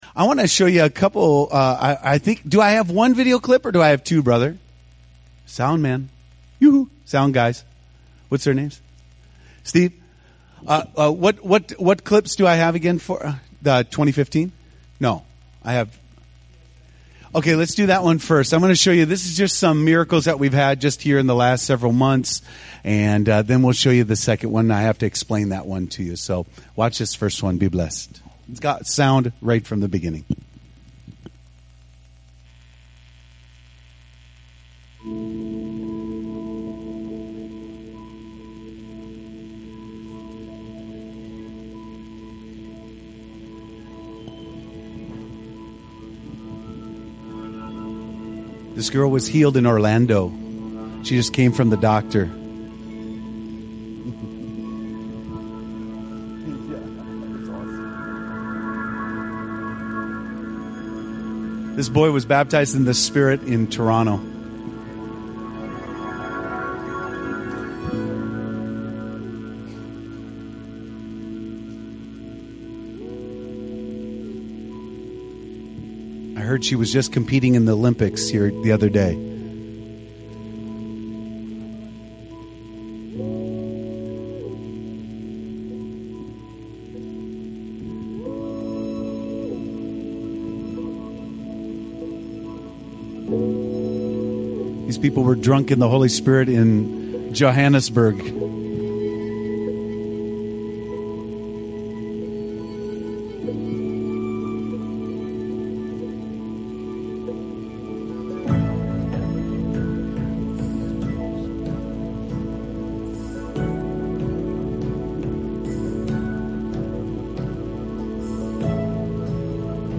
New Life Church, Rochester Indiana, A Spirit led, full gospel, Bible taught church in Rural North Central Indiana.
Sermon messages available online.